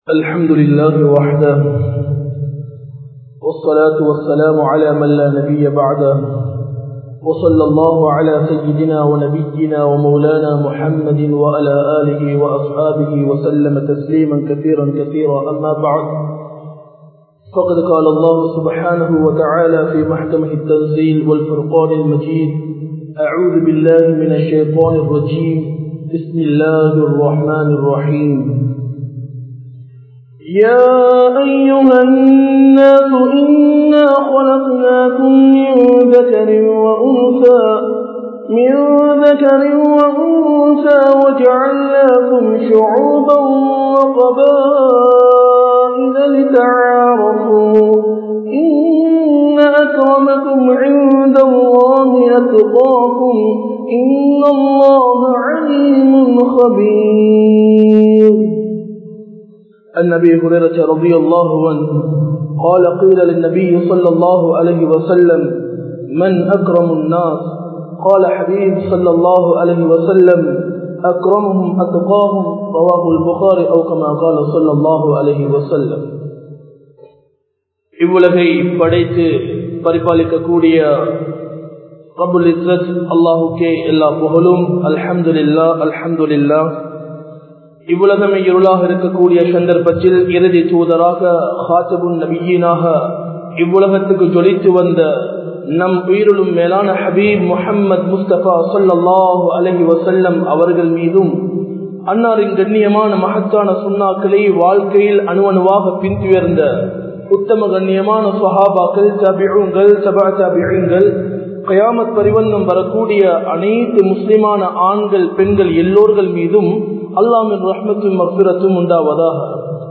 Manitharhalil Sirantha Manithan Yaar? (மனிதர்களில் சிறந்த மனிதன் யார்?) | Audio Bayans | All Ceylon Muslim Youth Community | Addalaichenai
Majma Ul Khairah Jumua Masjith (Nimal Road)